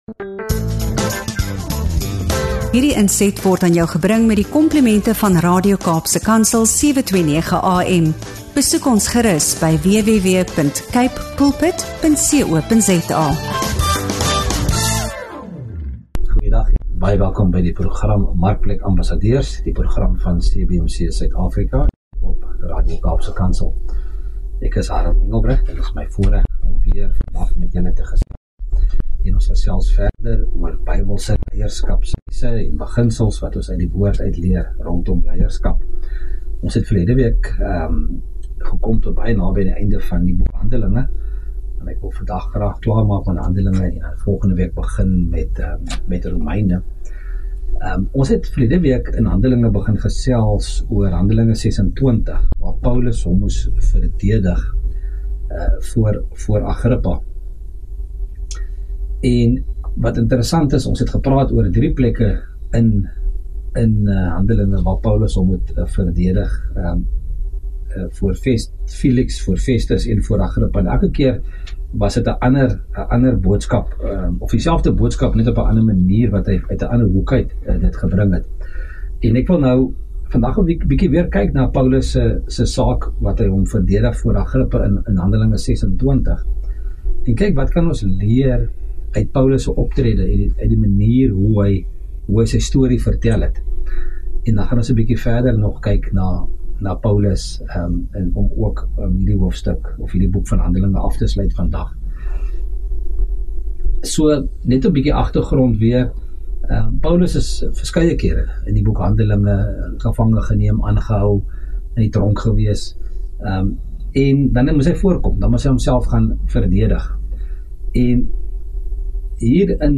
Die gesprek fokus op die krag van visie, hoe God se openbaring Paulus se lewe radikaal verander het, en wat moderne gelowiges—veral leiers en sakepersone—hieruit kan leer.